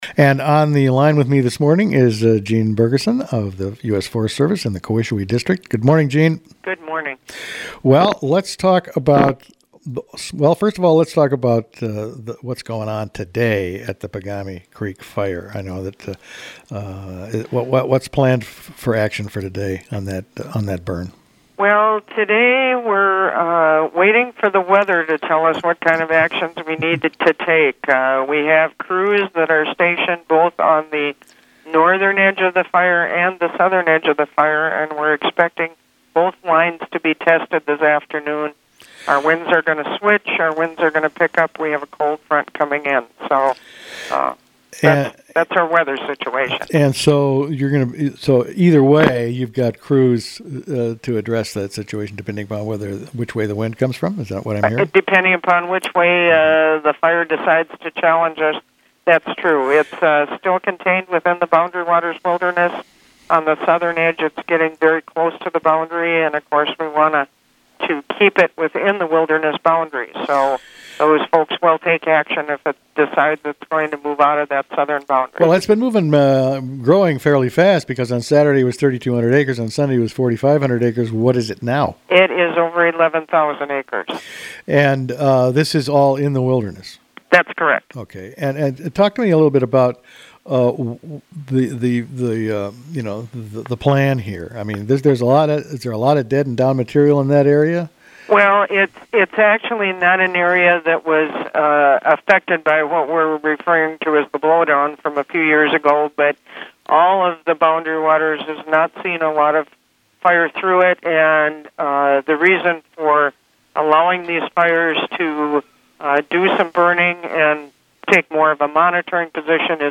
Pagami Creek Fire Update: campfire restrictions imposed | WTIP North Shore Community Radio, Cook County, Minnesota